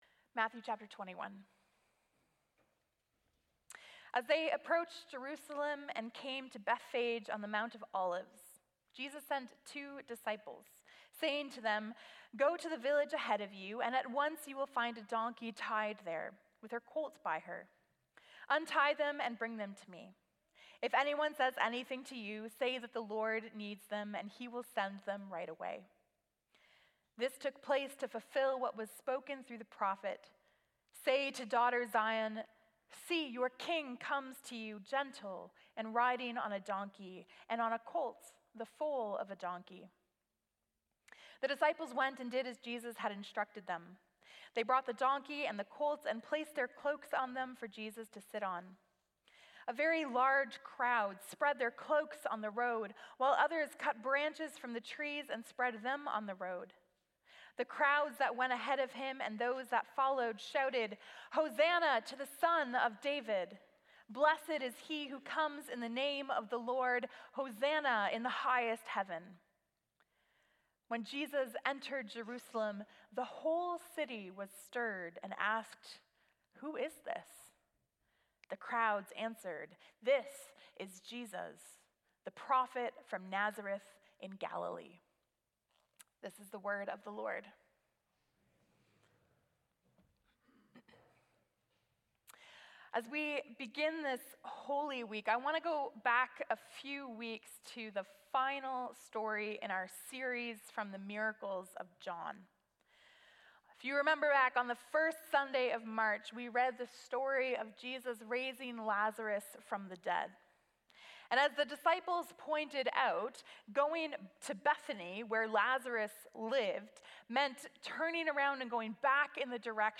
Sermons | Community Christian Reformed Church